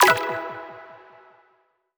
button-generic-select.wav